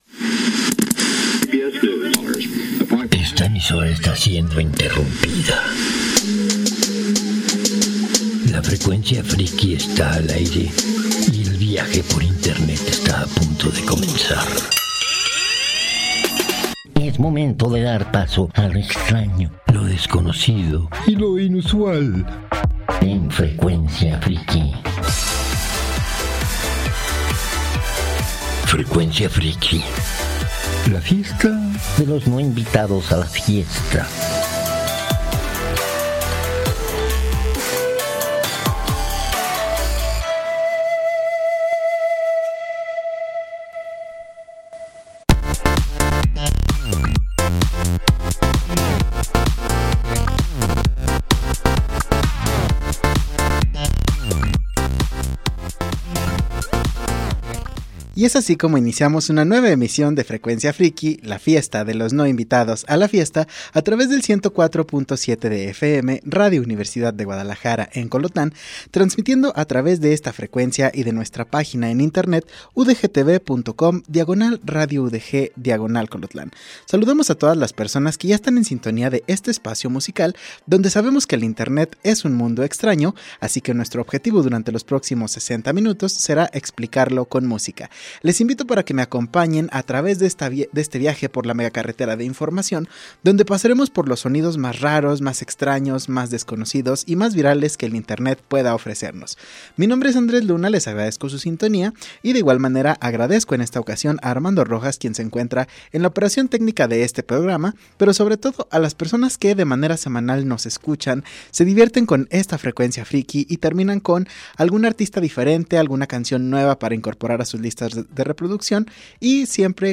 es un lugar donde nos encanta la música del mundo (pero sobretodo de China, Corea del Sur y Japón); es un programa para lo extraño, lo desconocido y lo inusual.